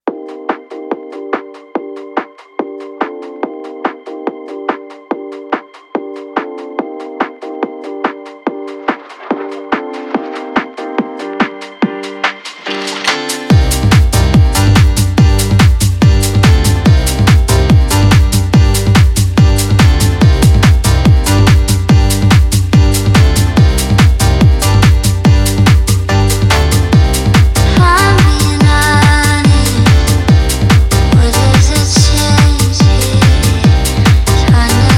Жанр: Танцевальные / Электроника
Electronic, Dance